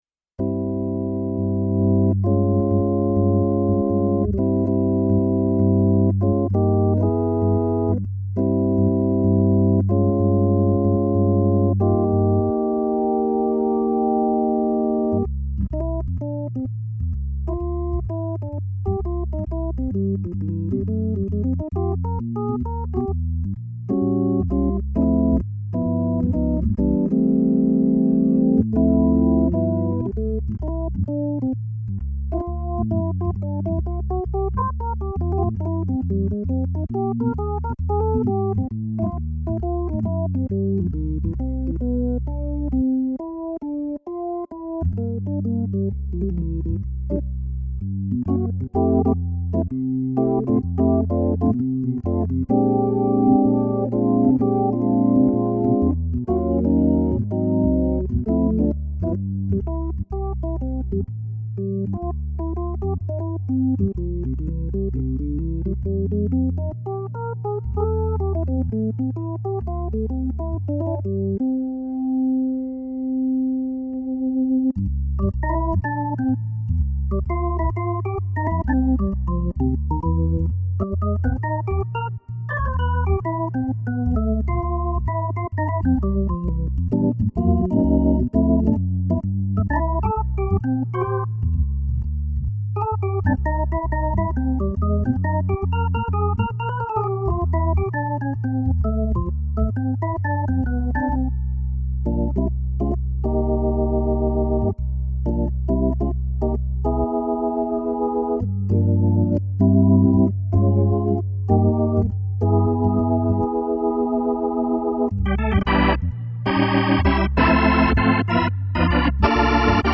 donc voici du vite fait de chez vite fait, bien entendu pas la moindre correction....je demarre sans aucun effet ni meme reverb ensuite j'ajoute de la simul de leslie...de la reverb, des fuites, du click, un poil d'overdrive, tout ça en direct sans couper l'enregistrement....enfin vous entendrez, ça vous donnera une idée pour ceux qui n'en avaient pas
merci d'utiliser un casque ou des enceintes correctes sinon, ça va faire bizarre dans vos HP de portables :)